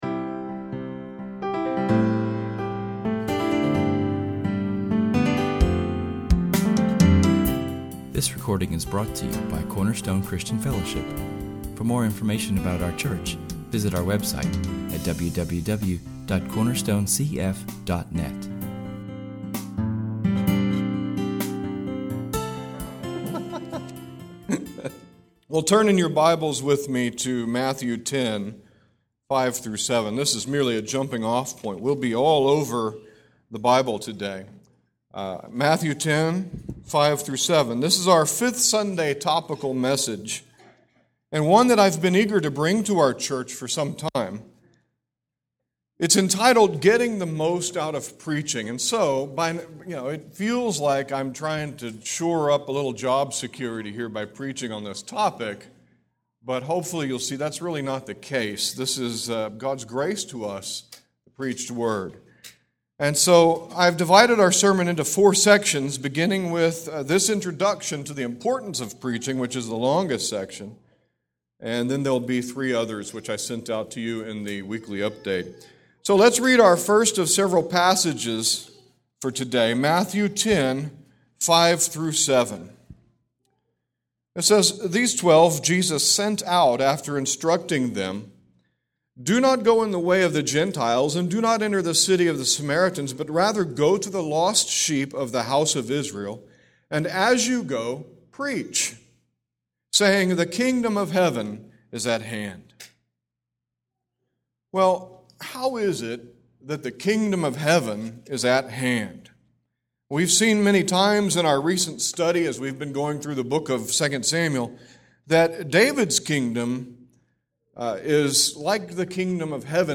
That means we take a planned break from our typical series and enjoy a topical sermon.